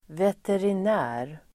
Ladda ner uttalet
veterinär substantiv (även som titel), veterinary surgeon [also as a title]Uttal: [veterin'ä:r] Böjningar: veterinären, veterinärerDefinition: djurläkare